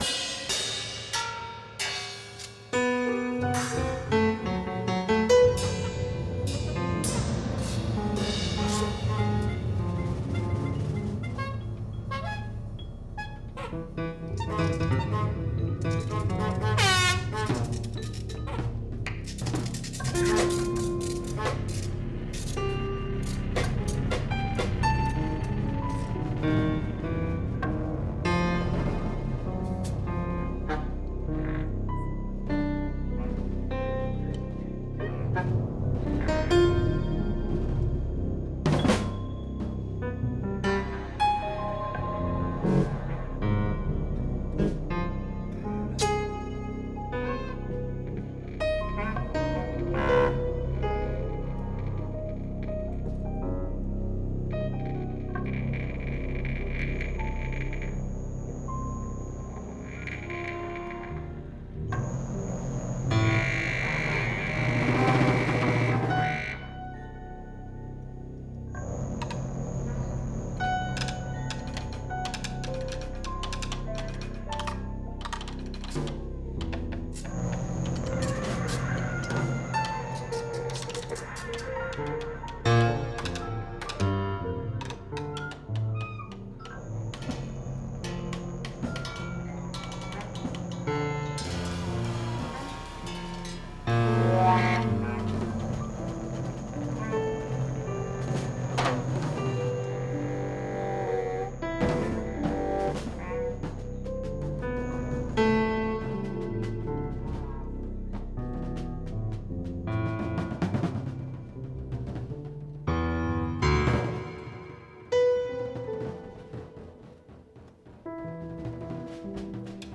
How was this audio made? A quick try, no adjustments to volume, pitch, timing or anything else.